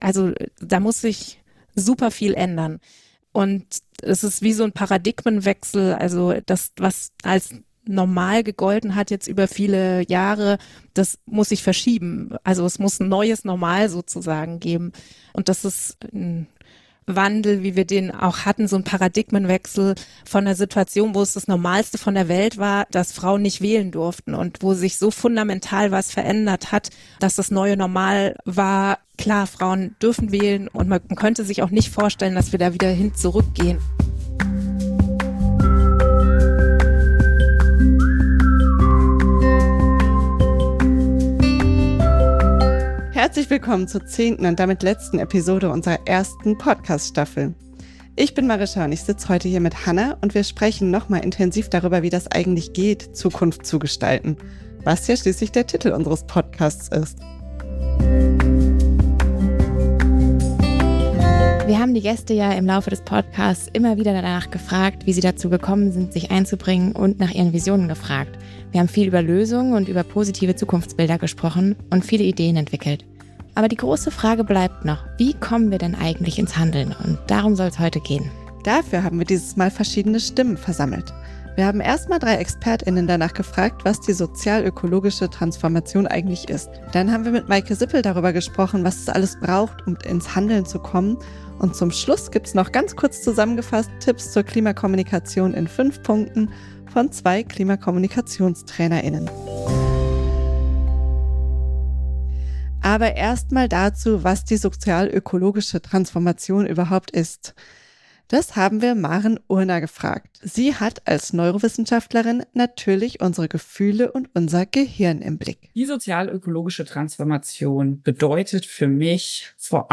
Beschreibung vor 7 Monaten In der zehnten und letzten Folge der ersten Staffel geht es darum, wie wir ins Handeln kommen und tatsächlich Zukunft gestalten können. Dazu haben wir drei Expert:innen eingeladen, um mit ihnen über die sozial-ökologische Transformation zu sprechen:
Als Einstieg hören wir verschiedene O-Töne zu der Frage, was die sozial-ökologische Transformation überhaupt ist: